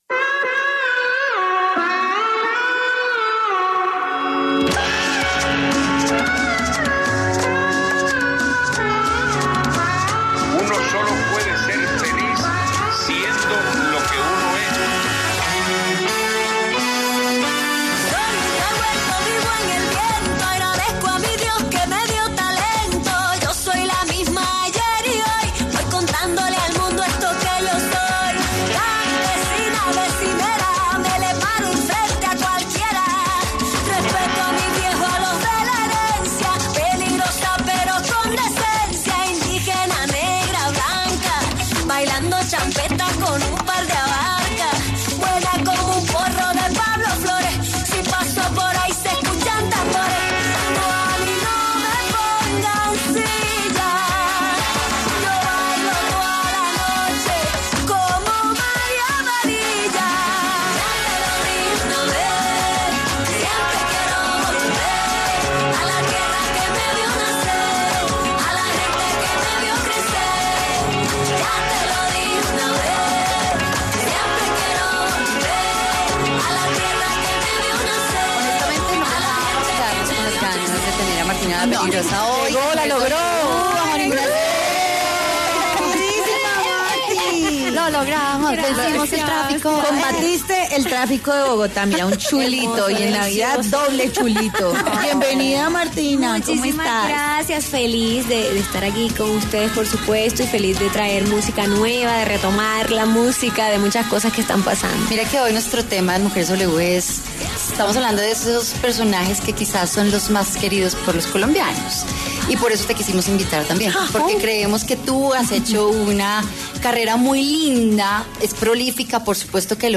Mujeres W conversó con Martina La Peligrosa, artista de talla internacional, quien contó de dónde viene su nombre artístico.